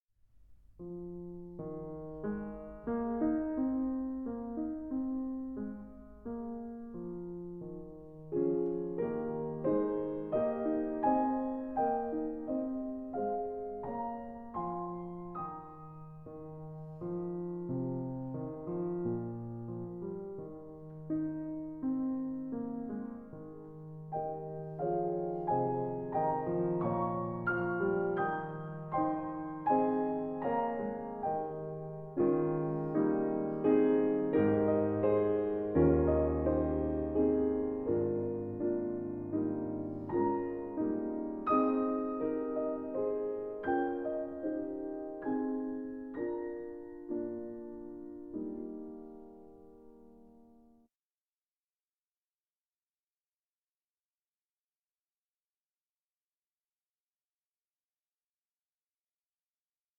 Celebrating Chamber Music
fresh and fragrant sounds